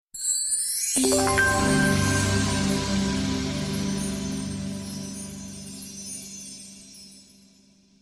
cute sms soft sms sweet sms nice sms